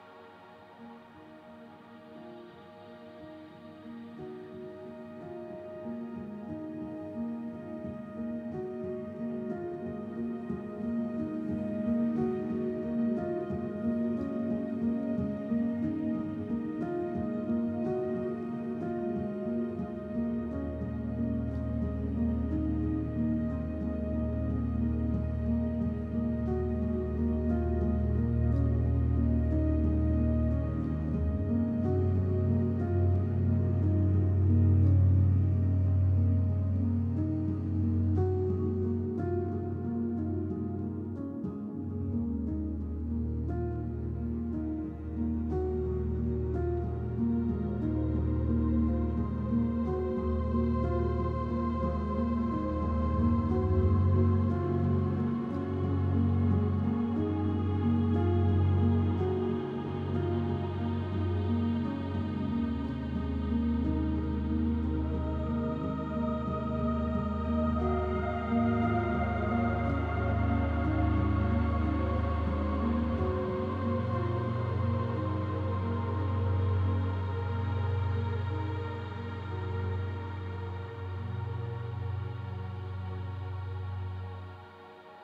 / 01 - Ambience / Safe House / Safe House 1 - Alter C - (L... 14 MiB Raw Permalink History
Safe House 1 - Alter C - (Loop).wav